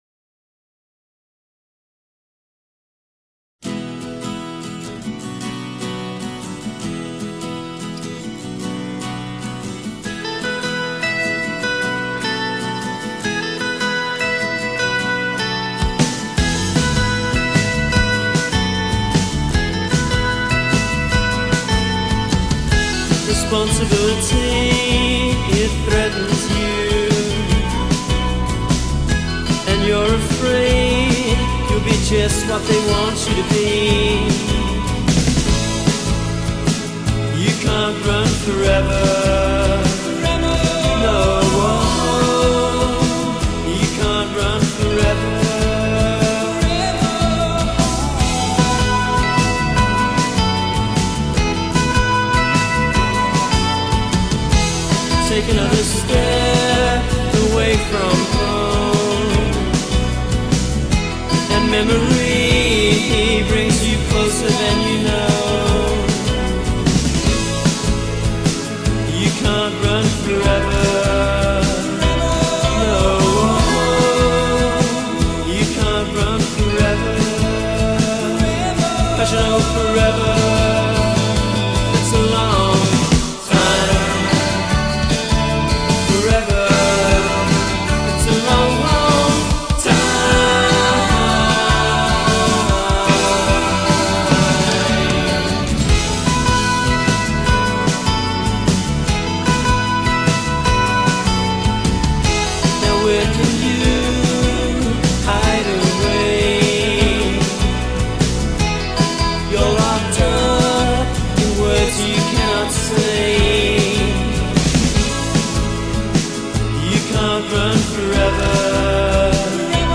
indie rock/indie pop band
bass and vocals
rhythm guitar and vocals
lead guitar
drums